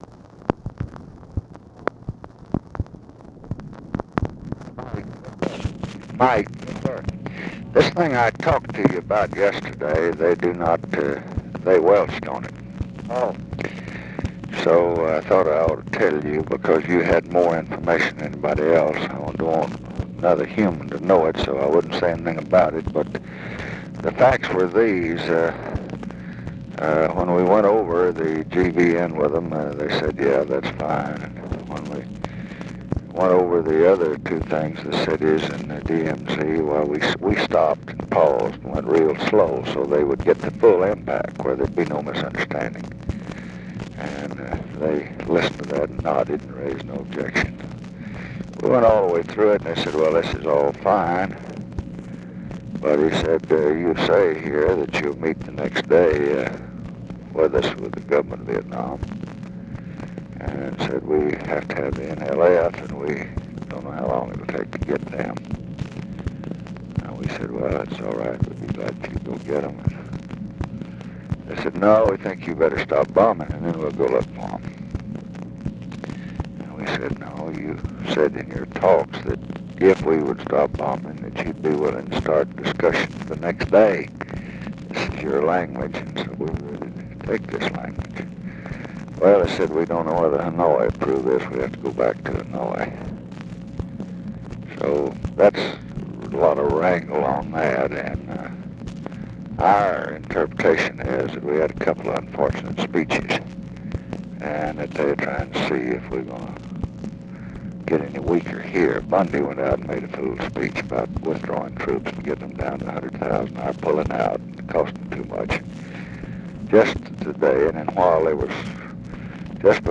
Conversation with MIKE MANSFIELD, October 16, 1968
Secret White House Tapes